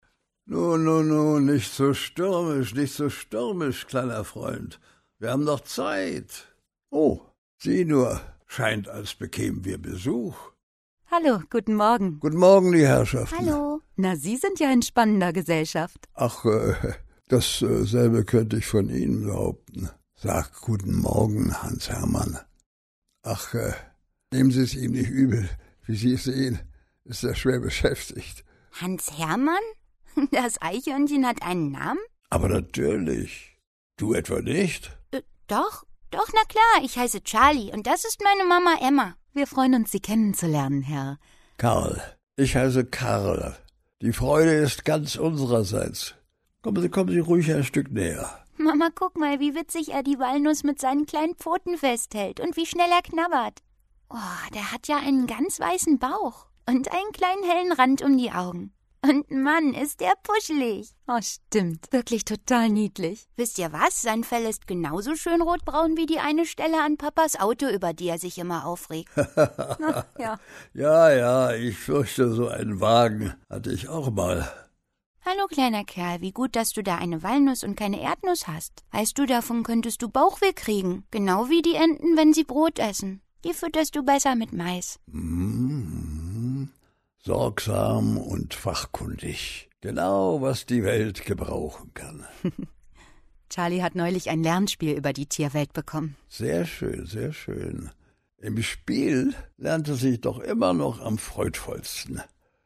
Auszug aus dem szenischen Rundgang